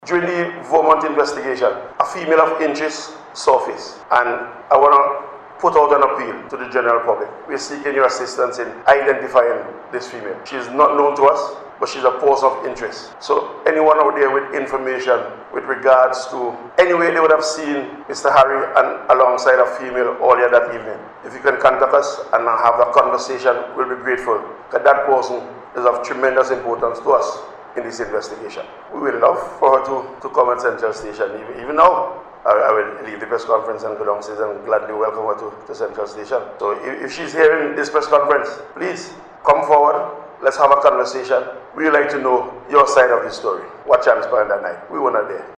At a press conference yesterday, Assistant Commissioner of Police in Charge of Crime, Trevor Bailey,